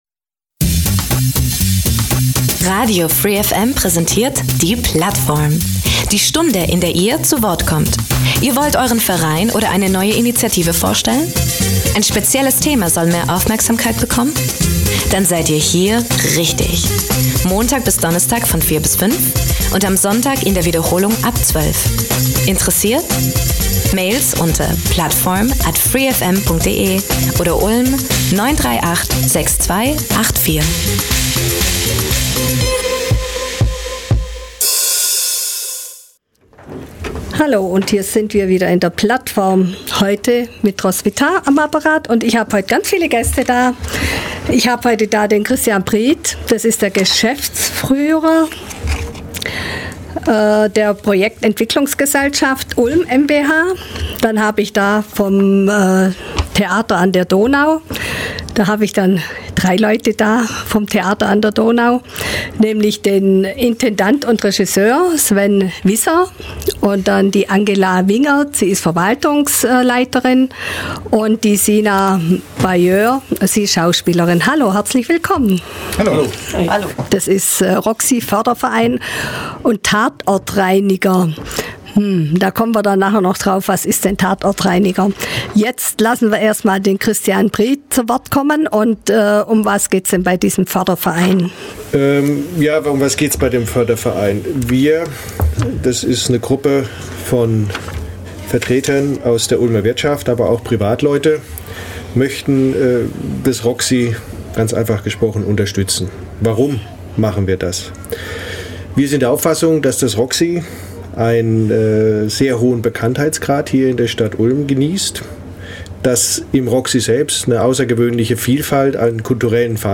Das Roxy war mal wieder zu Gast in der Plattform! Dieses Mal mit Vorstandsmitgliedern des neuen Roxy-Fördervereins und dem Team des Live-Hörspiels Der Tatortreiniger.